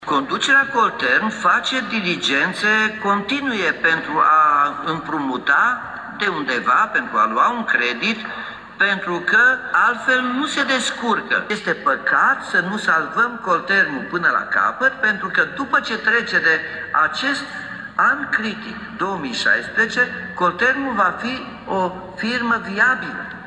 Surse din cadrul Primăriei Timișoara au declarat că după accesarea de către Colterm a unui credit de peste 70 de milioane de lei din trezorerie, se caută soluții pentru accesarea  unui nou împrumut de la Banca Europeană pentru Reconstrucție și Dezvoltare. Primarul Nicolae Robu confirmă intenția de accesare a noului împrumut: